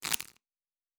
Cards Shuffle 1_02.wav